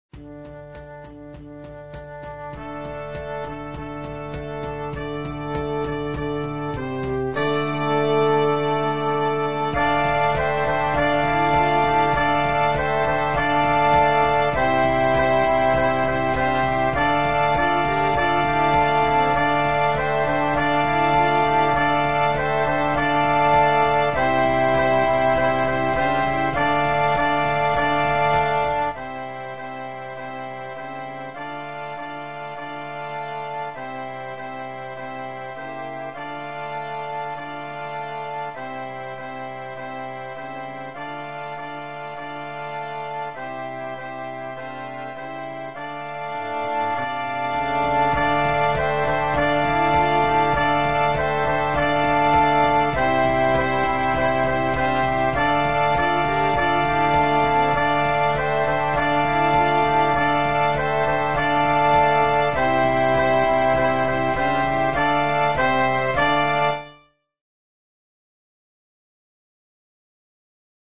Noten für flexibles Jugend Ensemble, 4-stimmig + Percussion.